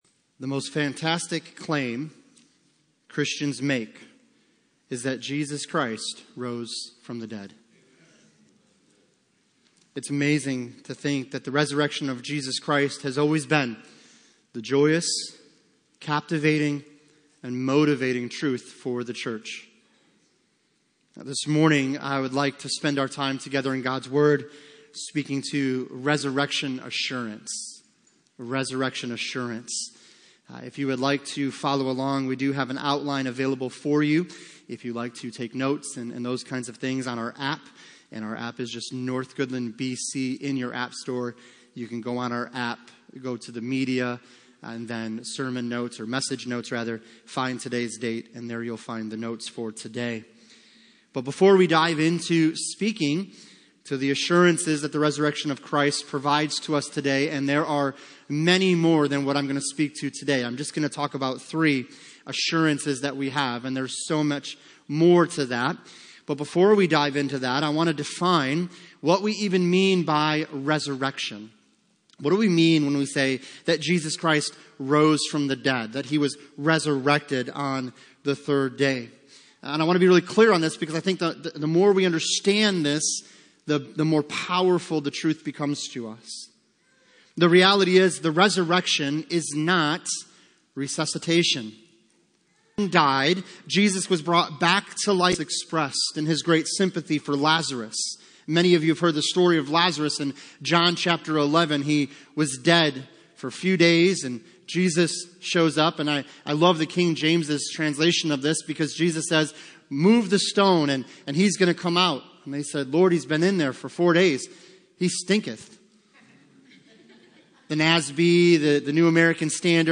Passage: 1 Corinthians 15:12-22 Service Type: Sunday Morning